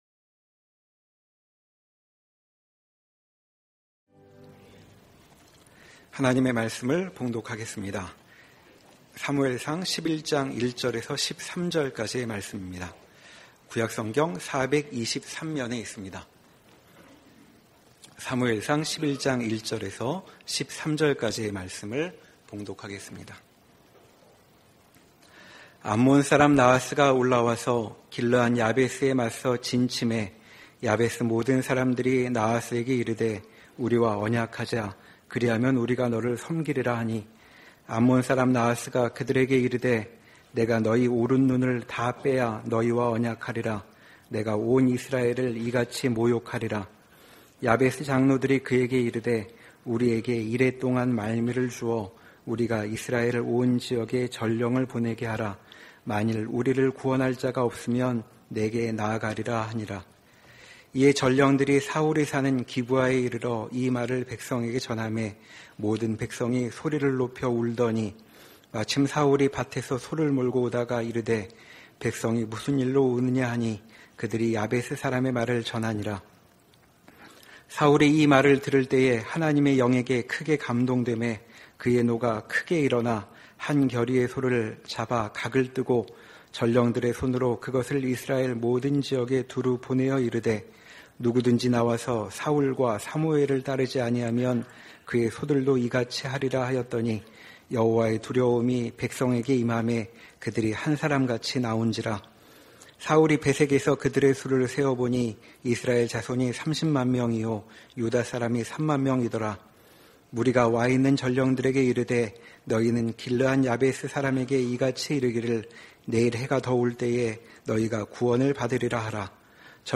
사무엘상 강해 16